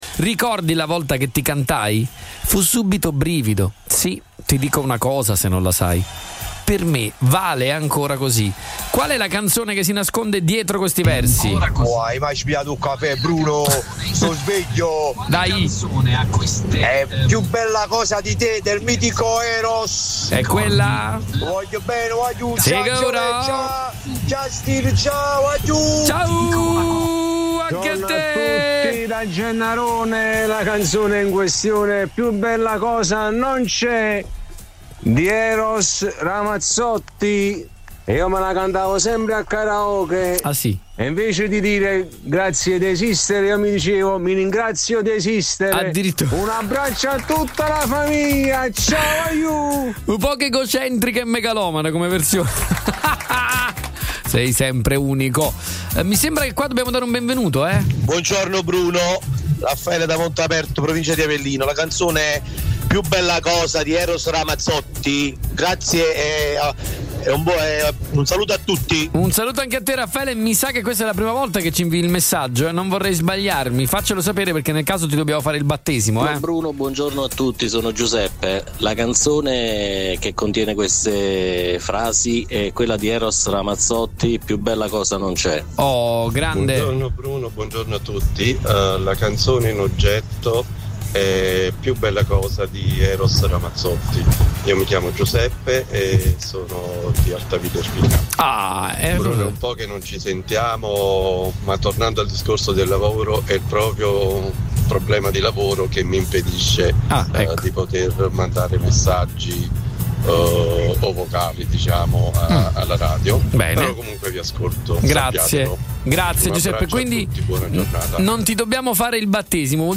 Podcast risposte ascoltatori